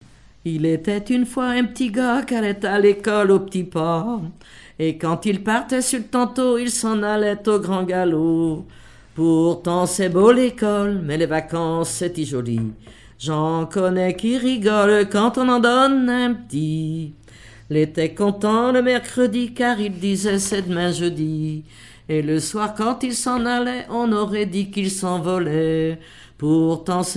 Enfantines diverses
Genre strophique
collectif de chanteuses de chansons traditionnelles
Pièce musicale inédite